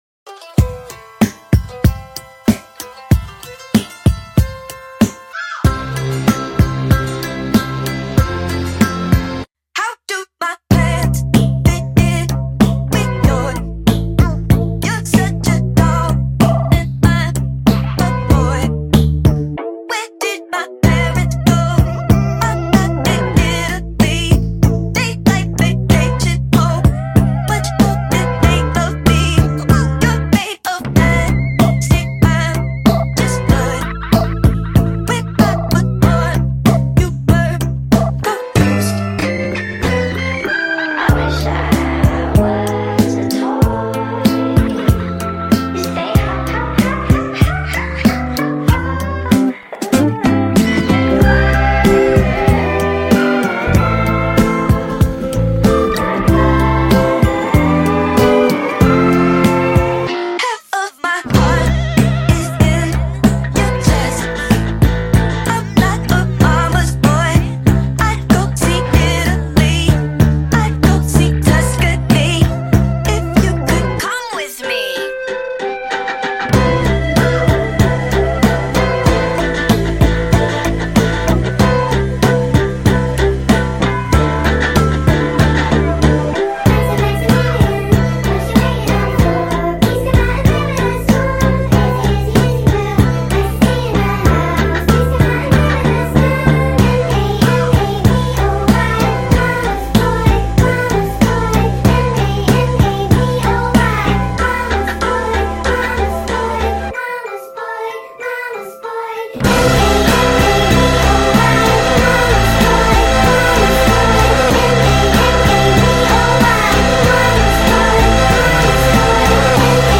Sped up and full song!